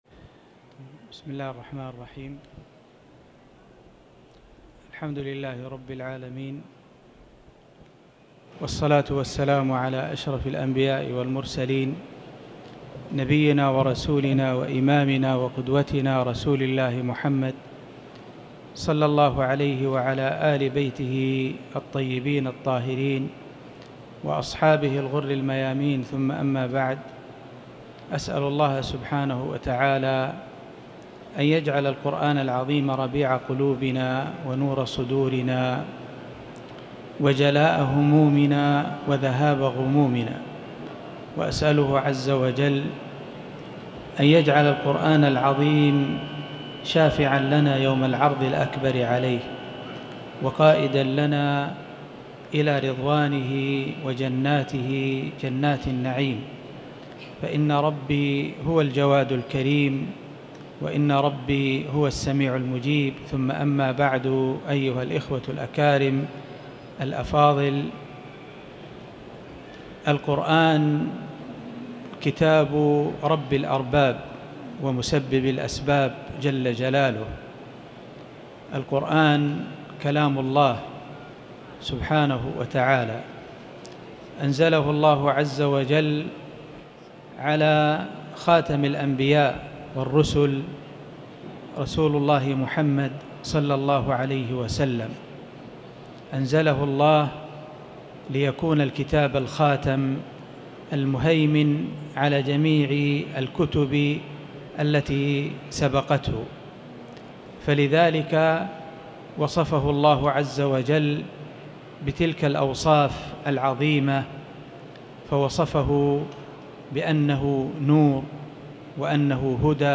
تاريخ النشر ١٤ محرم ١٤٤٠ هـ المكان: المسجد الحرام الشيخ